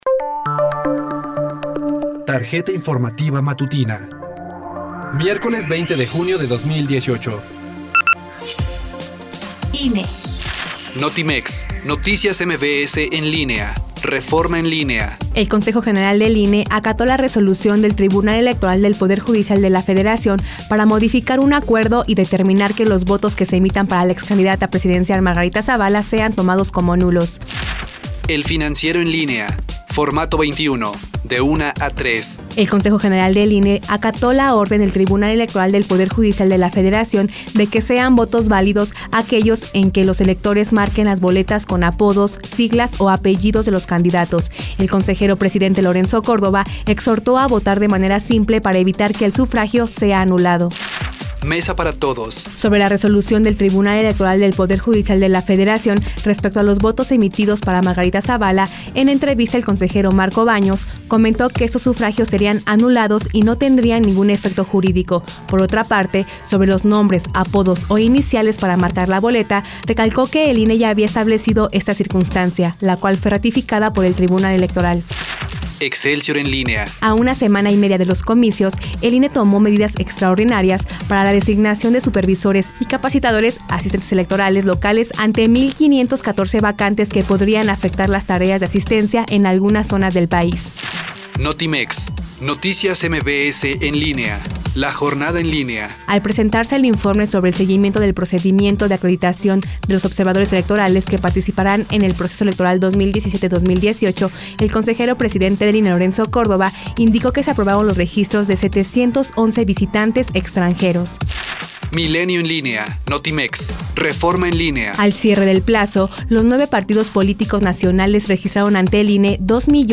Corte informativo vespertino, 20 de junio de 2018